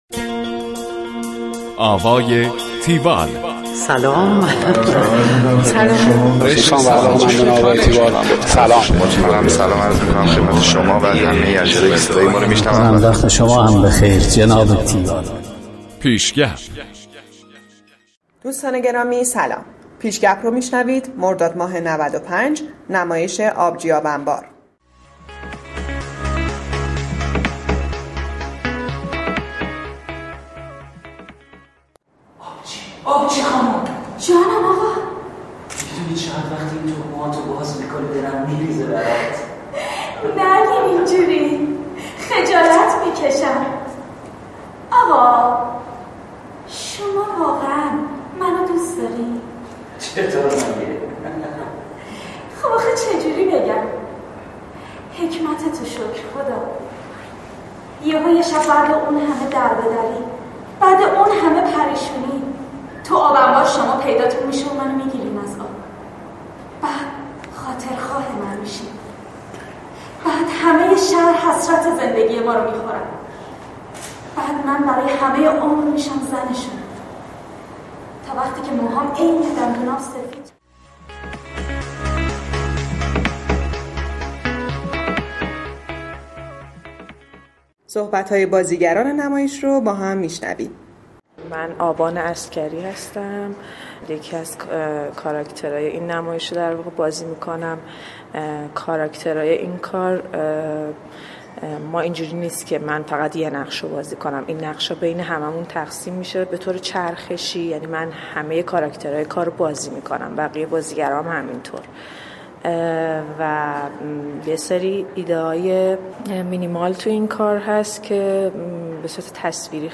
گزارش آوای تیوال از نمایش آبجی آب انبار
گفتگو با